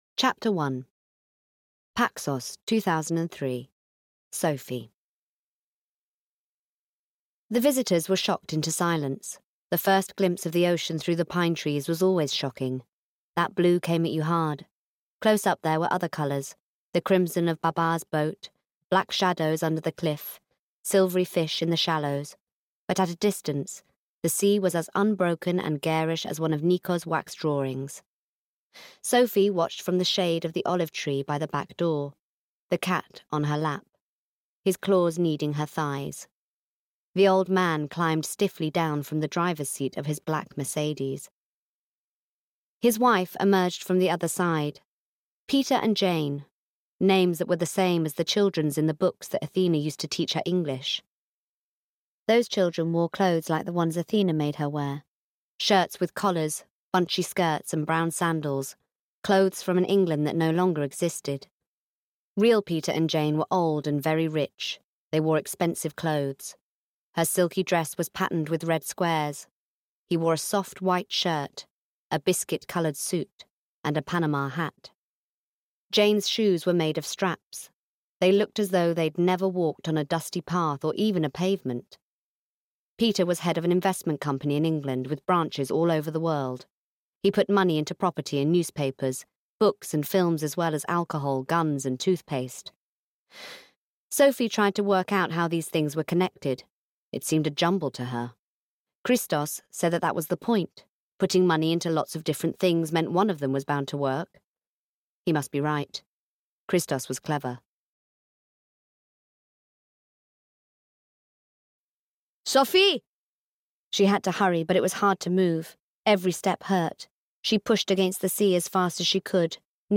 Voice Reel
Narration - All Her Secrets - Greek Accent Example